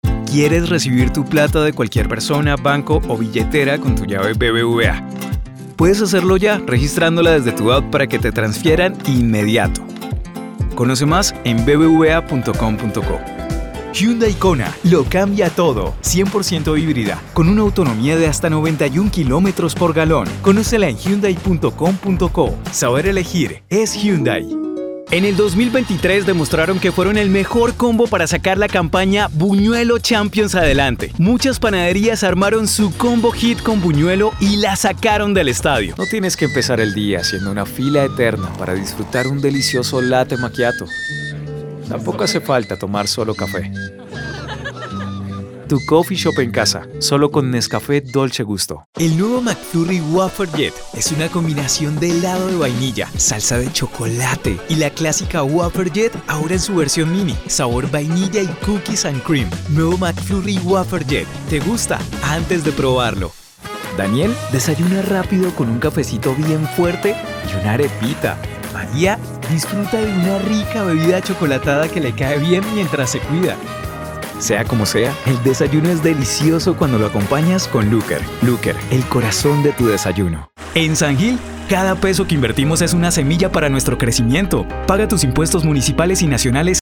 Artista de voz y doblaje, locutor y anunciador comercial, productor de audio y coach de voz. Español neutro Latino para spots publicitarios, documentales, video web, I.V.R.,